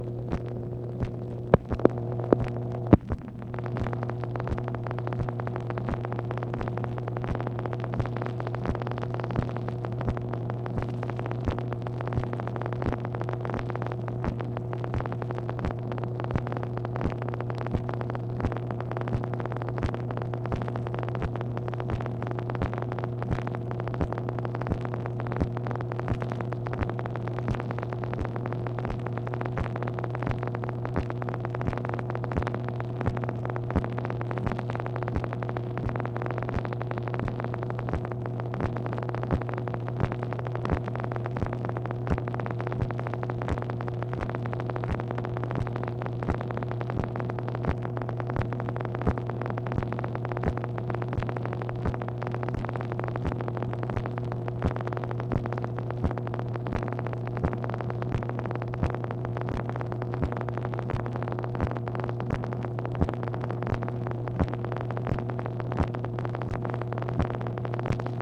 MACHINE NOISE, July 25, 1966
Secret White House Tapes | Lyndon B. Johnson Presidency